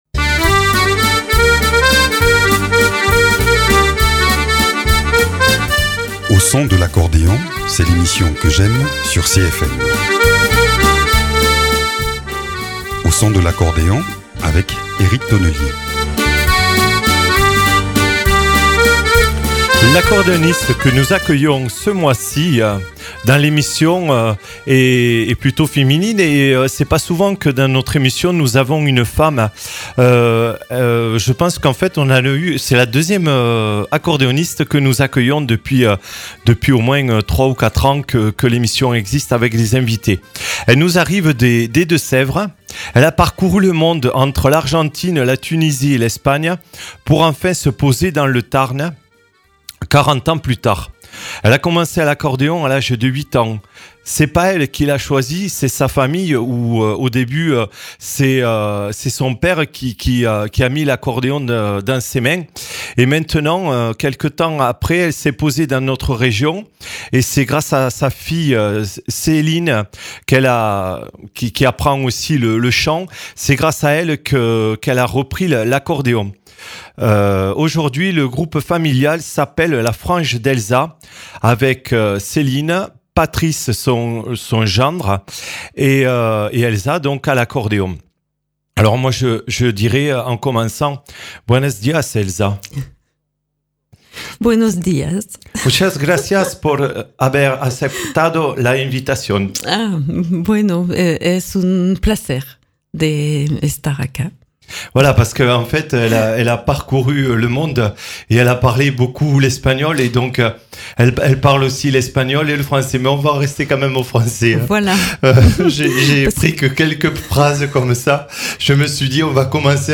accordéoniste